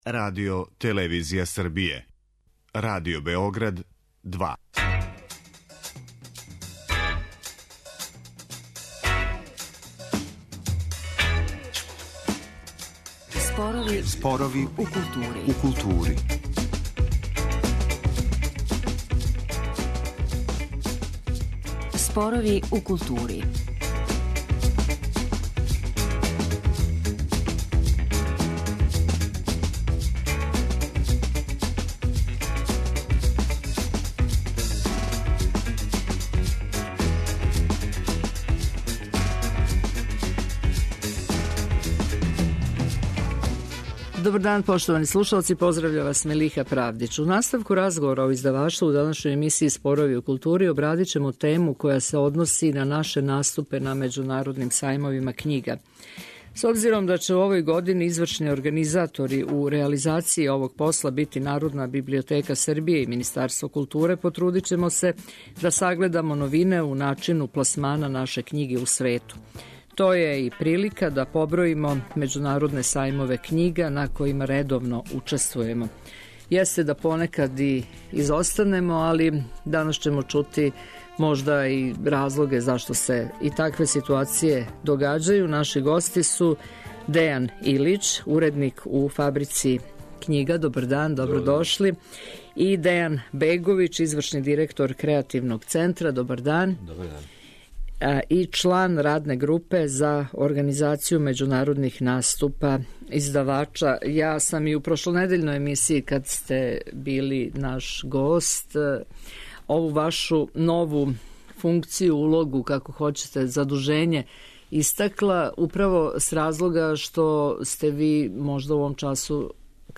У наставку разговора о издаваштву, у данашњој емисији обрадићемо тему којa се односи на наше наступе на међународним сајмовима књига.